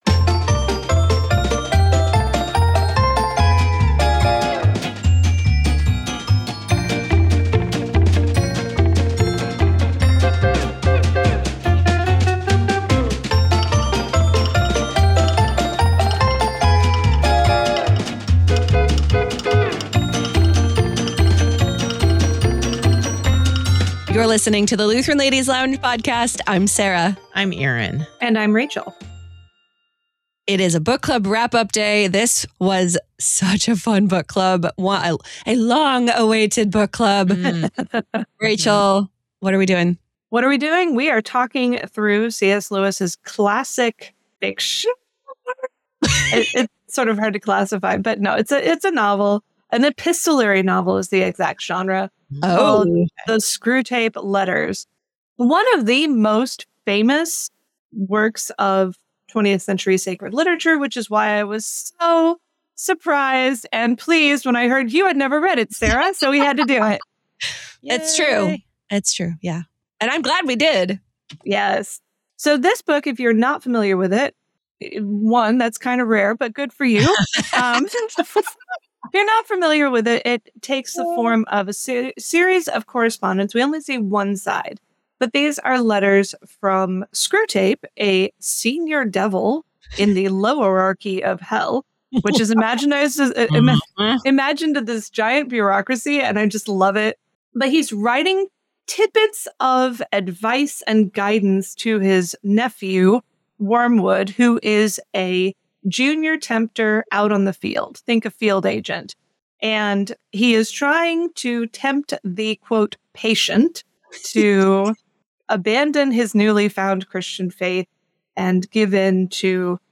book club recap conversation